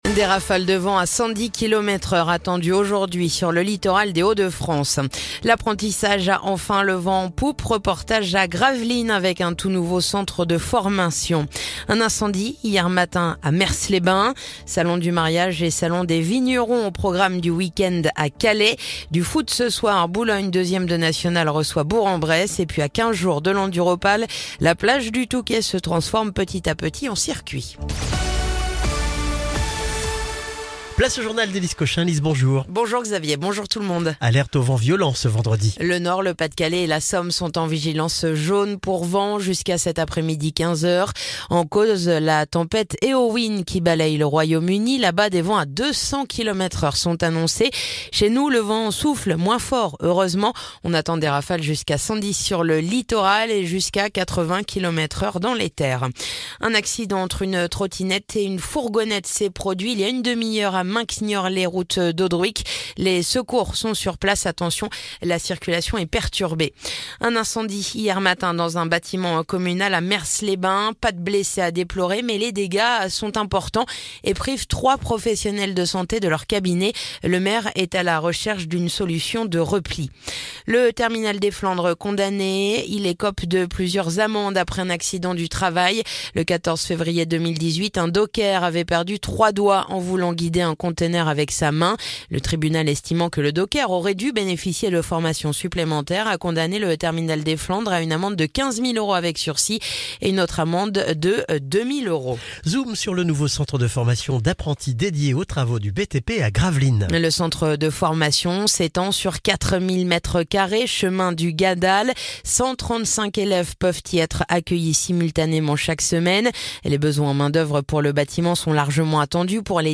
Le journal du vendredi 24 janvier